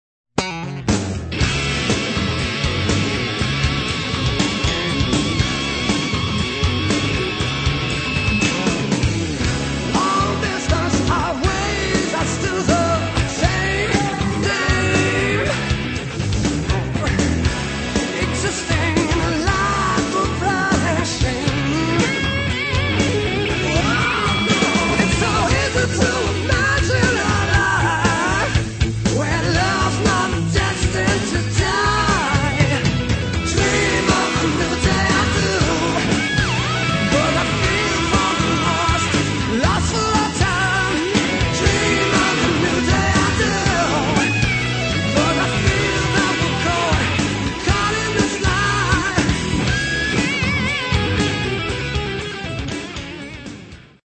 Drums
Recorded at: Prairie Sun, Cotati
Mixed at: Coast Recorders, San Francisco